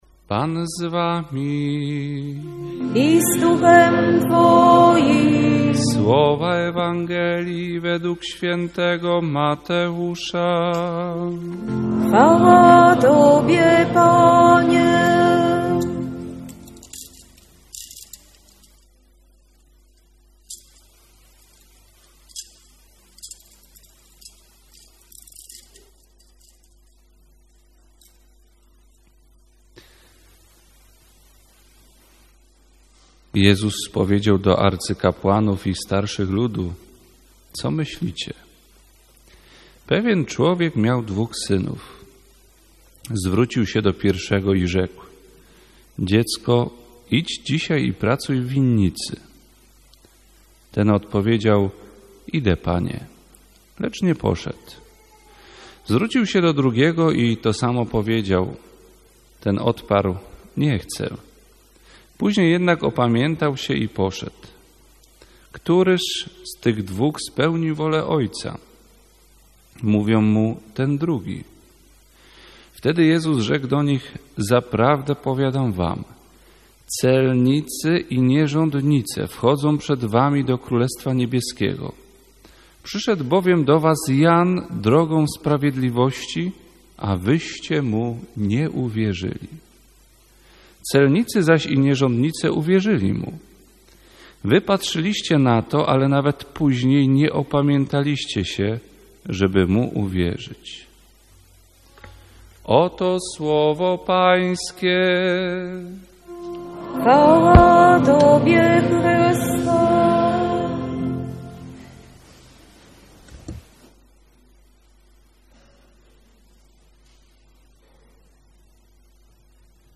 Coroczne celebrowanie uroczystości odpustowej ku czci św. Rafała Archanioła, w tym roku odbyło się w niedzielę 1 października 2023r. w kościele pw. Bożego Ciała w Zabrzu-Kończycach.